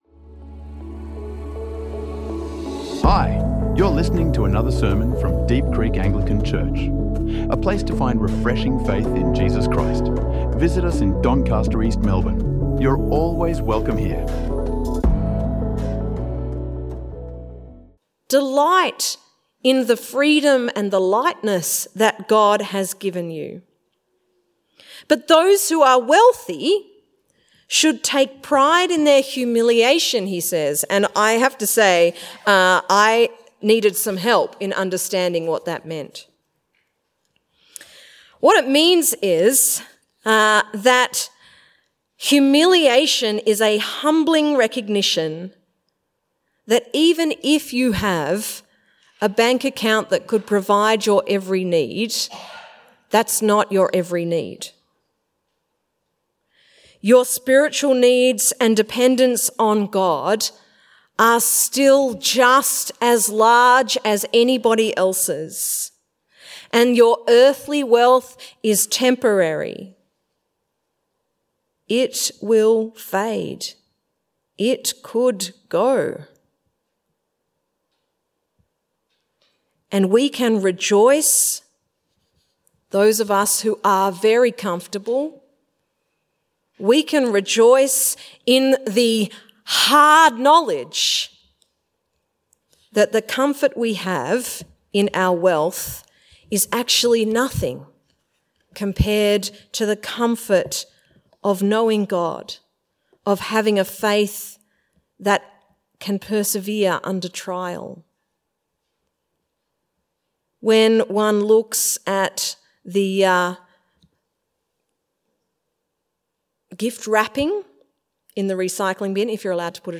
Faith in Action | Sermons